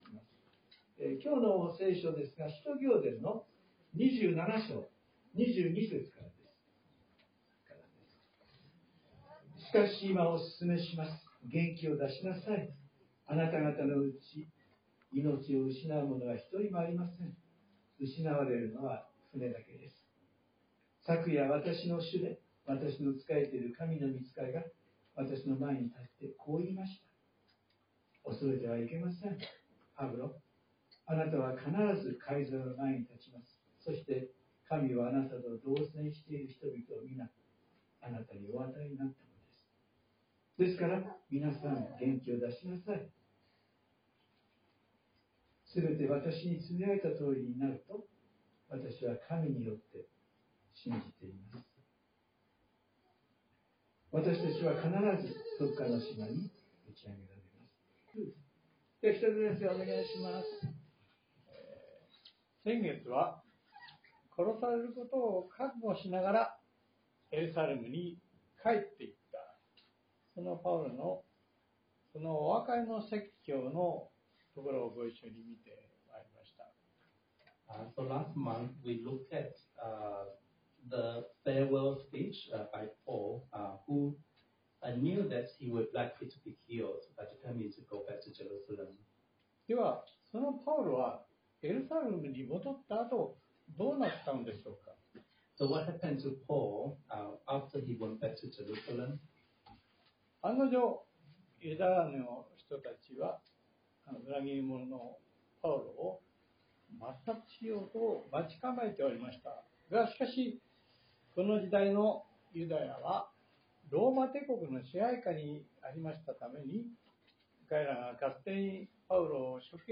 ↓Audio link to the sermon:(Sunday worship recording) (If you can’t listen on your iPhone, please update your iOS) Sorry, this post is no translate, only available in Japanese.